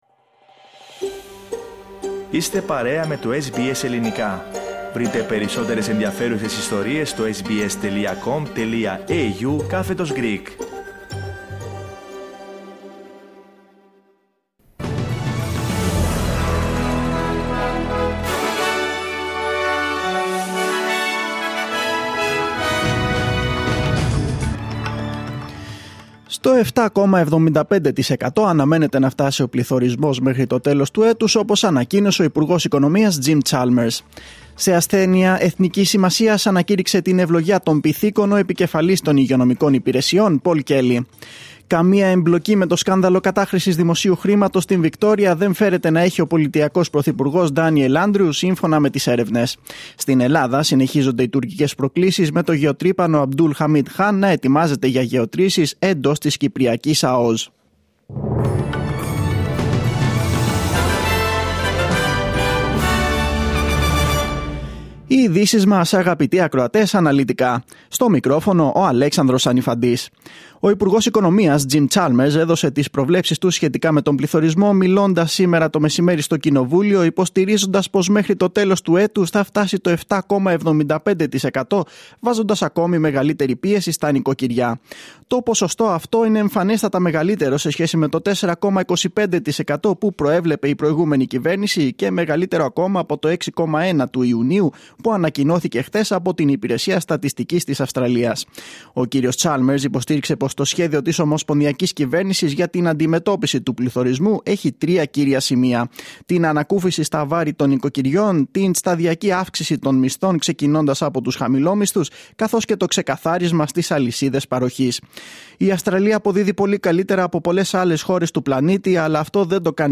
Δελτίο Ειδήσεων Πέμπτη 28.7.2022
News in Greek. Source: SBS Radio